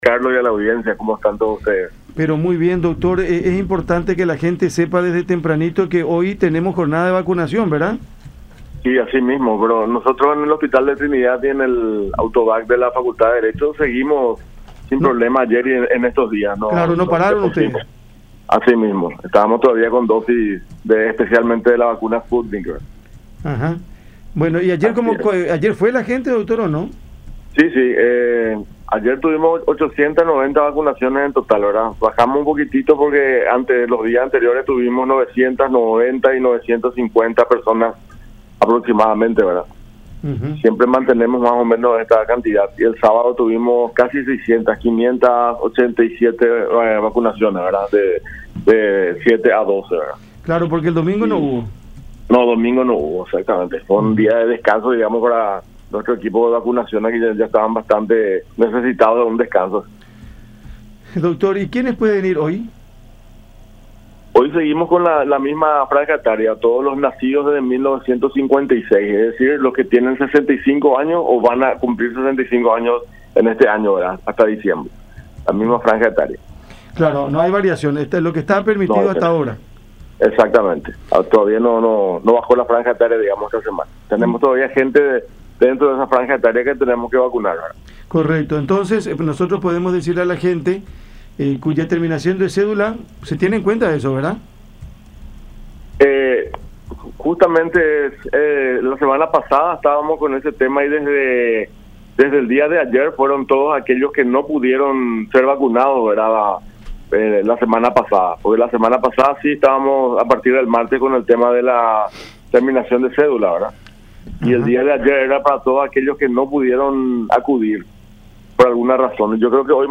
en conversación con Cada Mañana por La Unión. La campaña de inmunización contra la influenza comenzó el pasado 21 de mayo.